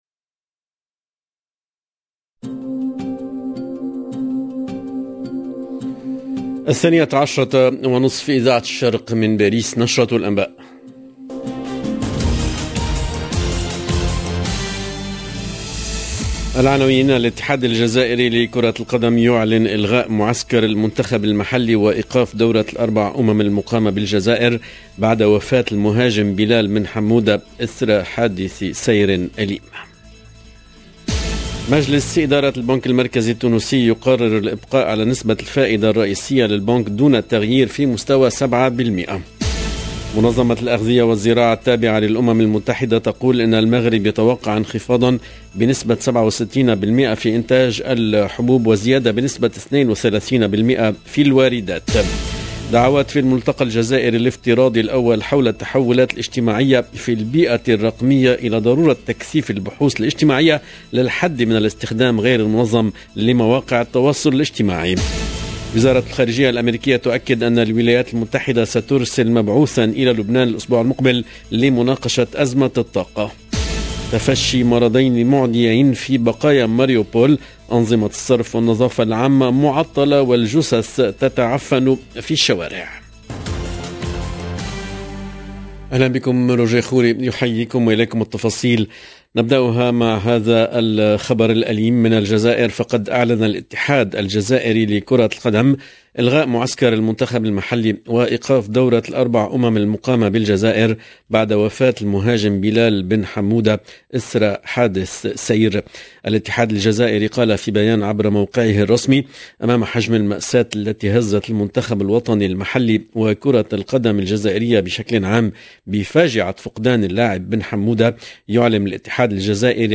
LE JOURNAL EN LANGUE ARABE DE MIDI 30 DU 11/06/22